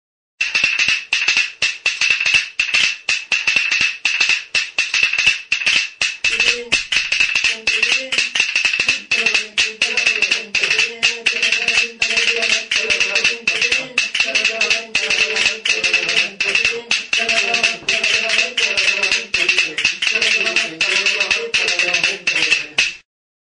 Idiophones -> Frappés -> Indirectement Emplacement
Description: Zurezko bi tablatxo dira. Hauetako batek bi muturrak errebajaturik dauzka.